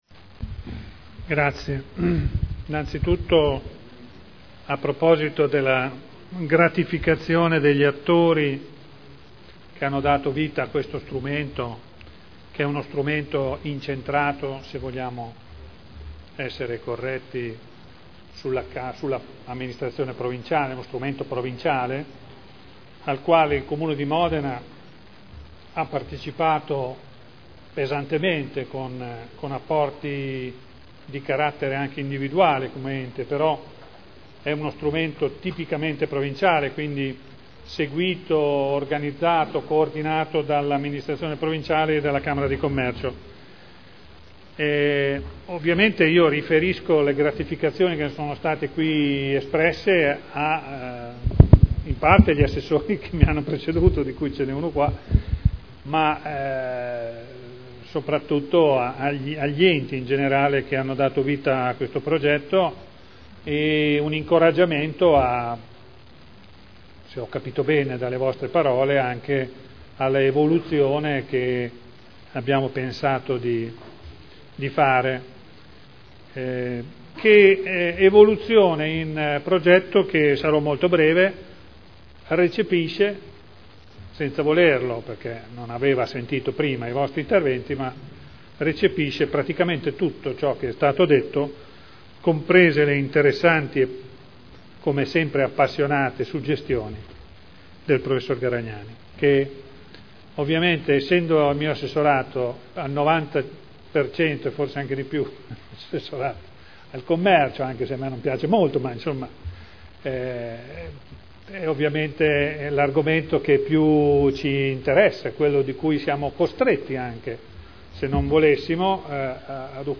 Seduta del 19/09/2011. Dibattito su proposta di deliberazione. Fondo provinciale per il sostegno all’innovazione delle imprese – Approvazione dello schema di convenzione per il rinnovo del fondo rotativo